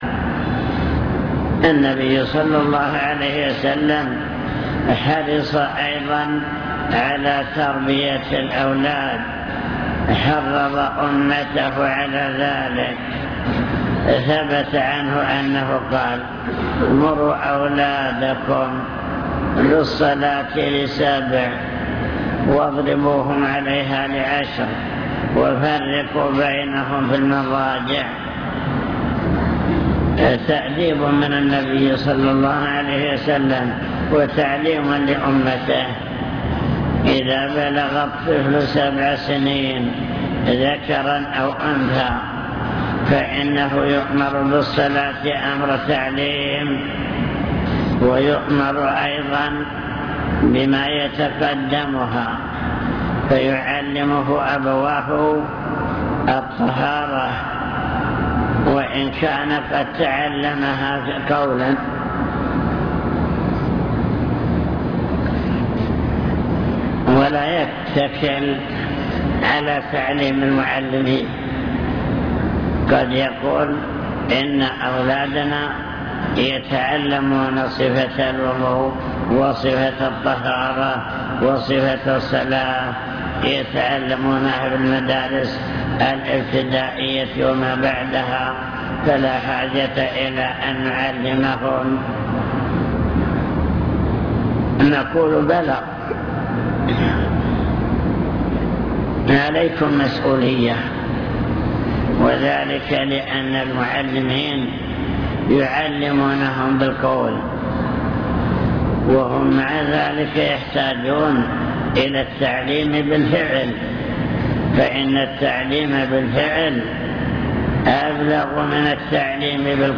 المكتبة الصوتية  تسجيلات - محاضرات ودروس  مسئولية الآباء تجاه الأبناء